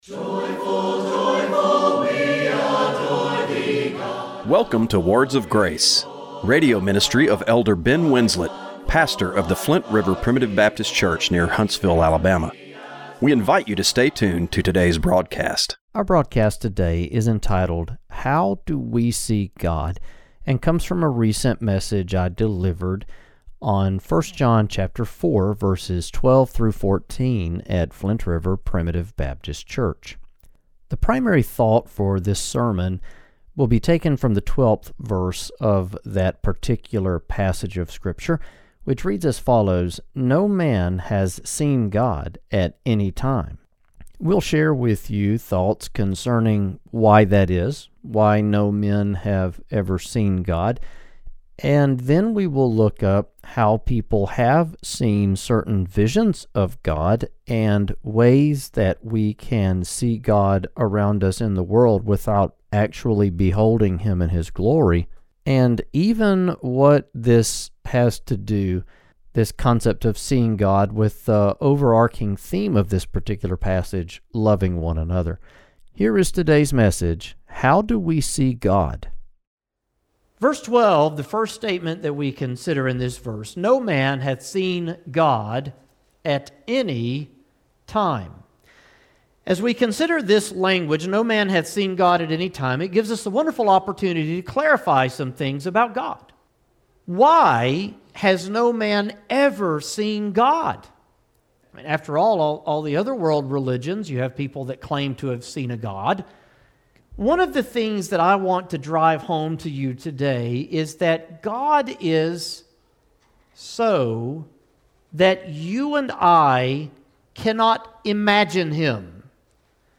Radio broadcast for December 8, 2024.